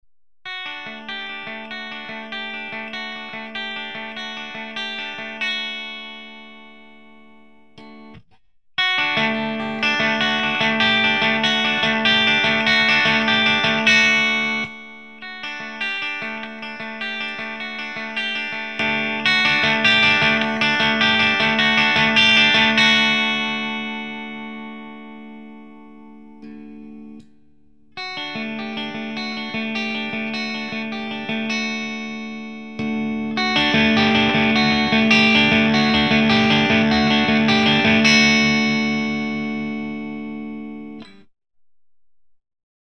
ギターはFERNANDES MG-120Xです。
MTRはMRS-8を使いました。マイクはSM57 PG57
ゲインを下げてブリッジミュートしたりしなかったりでサウンドの変化をつけました。
その分ミドルにパンチがあります。
GAIN2 Bass8 Middle10 Treble7
CLASSIC GAIN
クリーンはネック側のEMG85も入れてあります。